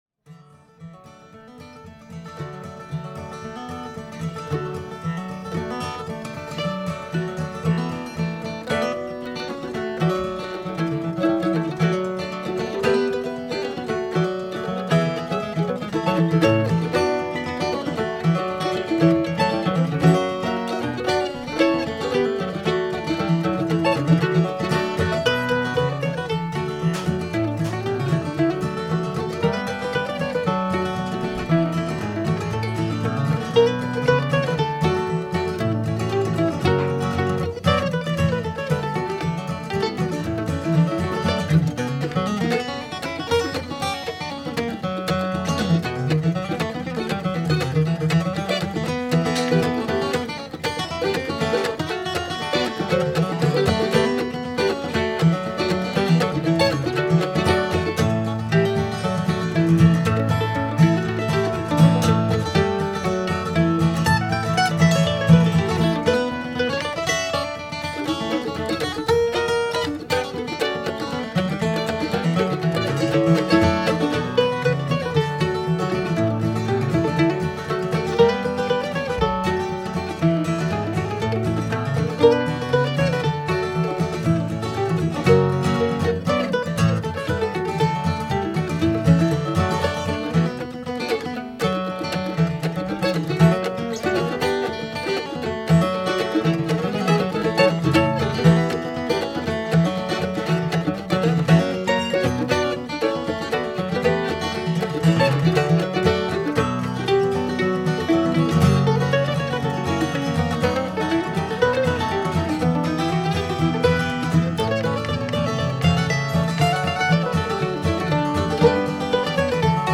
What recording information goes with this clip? live at Karl Hall in Wilkes Barre, PA.